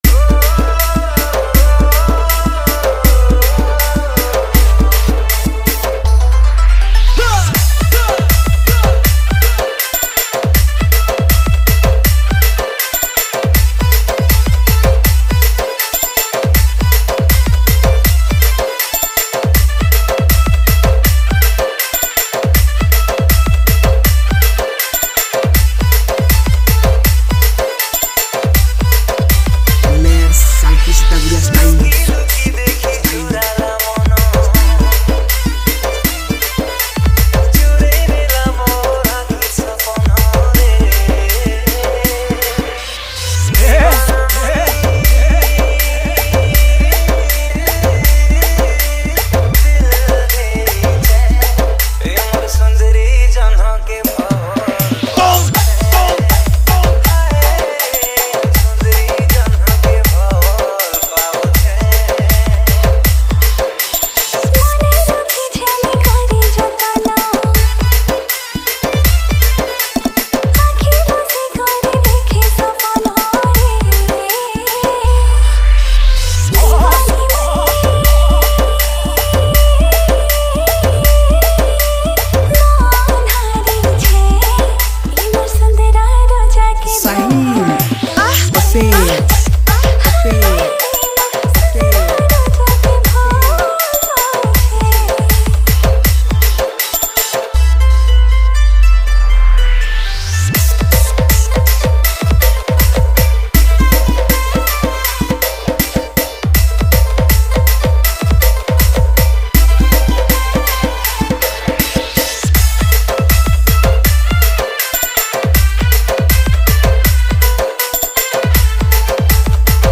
Category:  Sambalpuri Dj Song 2024